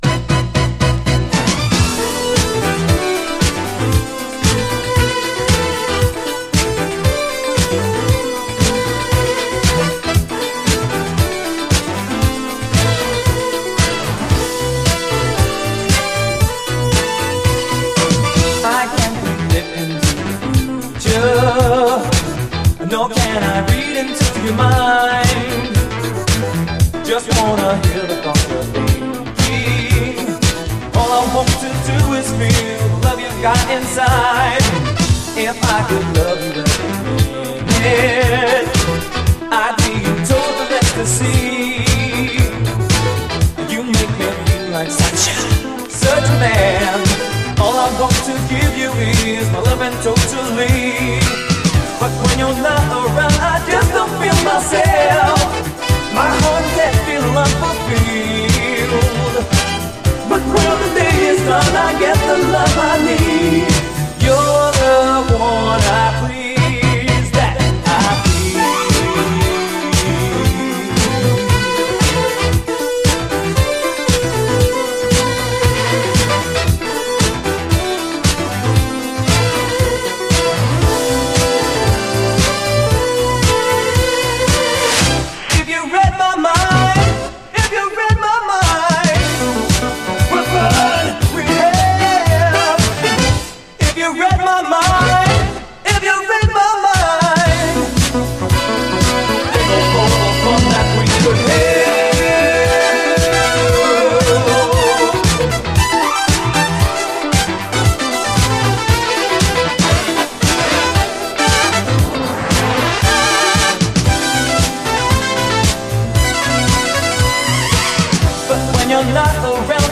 SOUL, 70's～ SOUL, DISCO, 7INCH
ギッラギラのシンセが強烈な光を放つ、最高シンセ・モダン・ブギー・クラシック！
ギッラギラのシンセが強烈な光を放つ、文句無しでめちゃくちゃカッコいい80’Sモダン・ソウル！